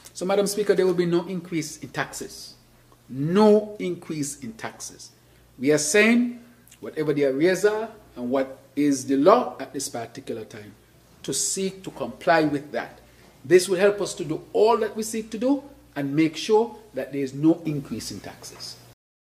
That was Prime Minister and Minister of Finance, the Hon. Dr. Terrance Drew during the 2025 Budget Address as he discussed the tax priorities for tax in 2026, stating the primary focus is to collect outstanding tax arrears.